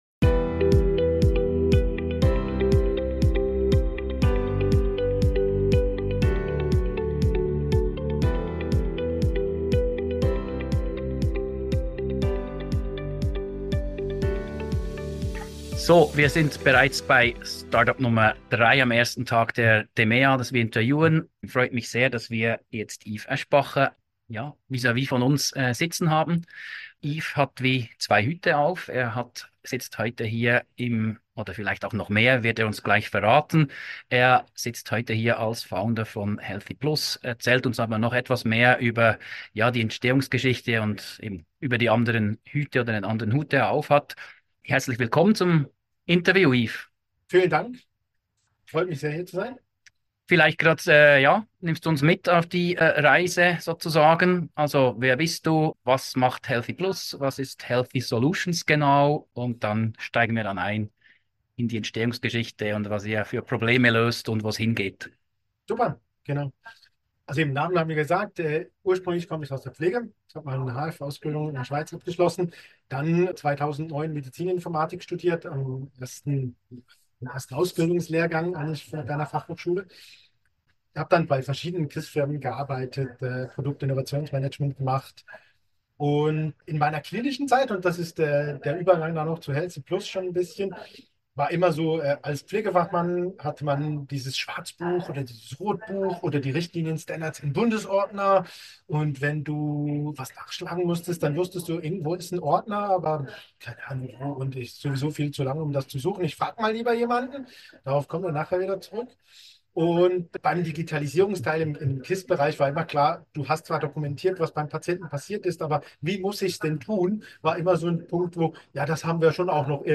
Kompakt Interview 02: Smarte Pflegeprozesse & KI in der Notaufnahme ~ Rocketing Healthcare: Startups & Innovationen im Gesundheitswesen Podcast